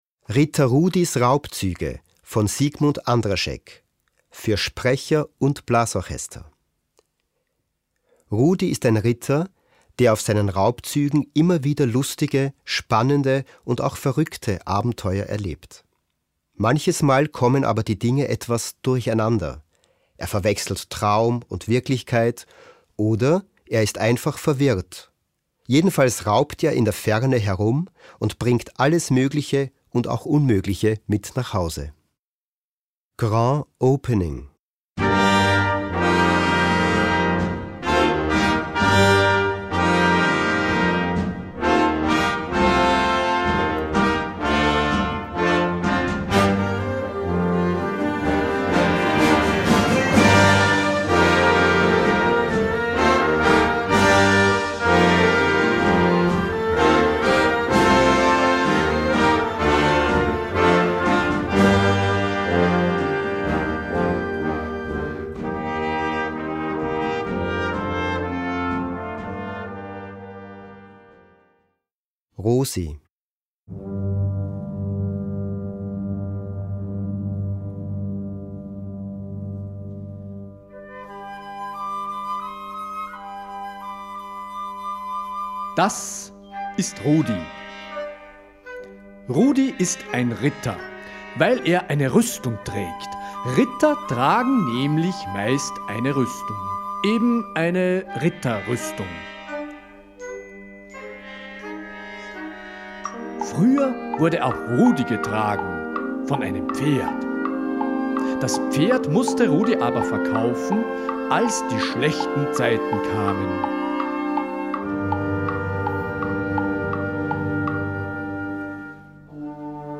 Gattung: Für Sprecher und Blasorchester
Besetzung: Blasorchester